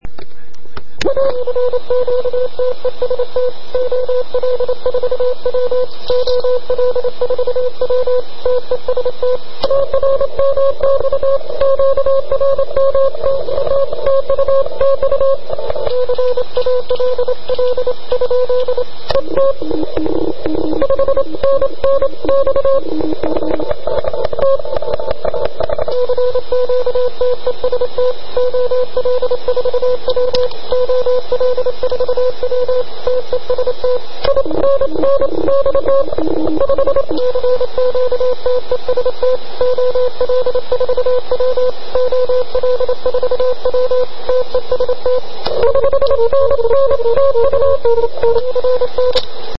Pásmo 40m.
QRM pro OL4W  (250 kB )
QRM.mp3